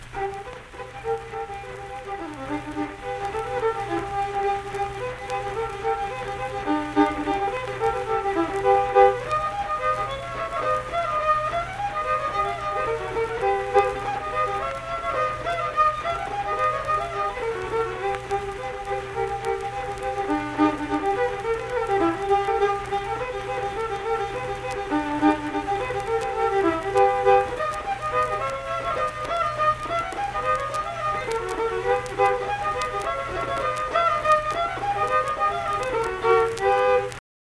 Unaccompanied vocal and fiddle music
Verse-refrain 3(2).
Instrumental Music
Fiddle
Pleasants County (W. Va.), Saint Marys (W. Va.)